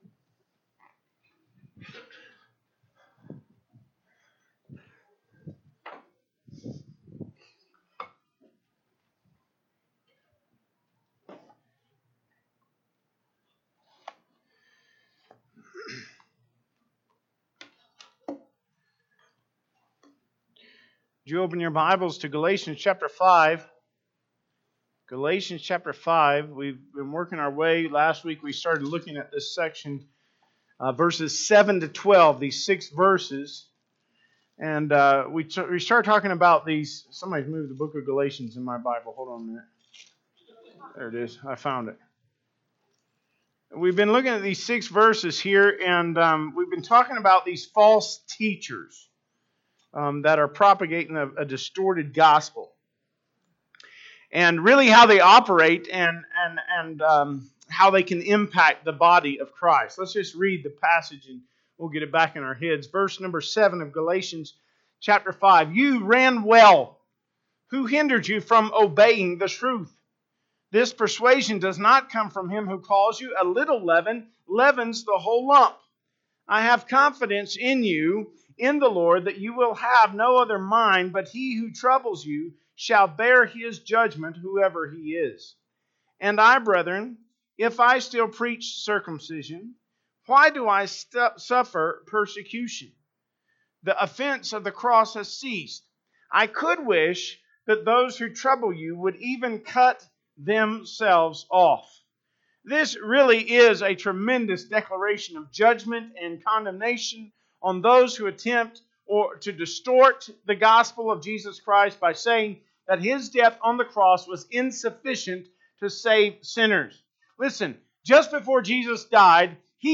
Sermons - Smith Valley Baptist Church